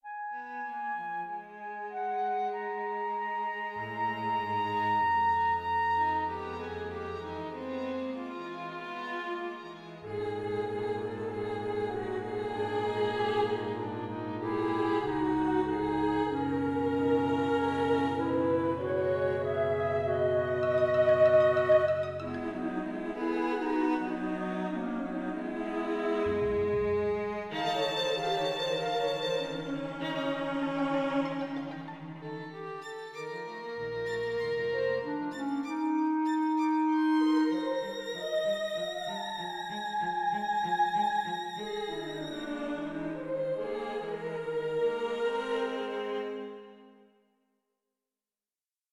Harpist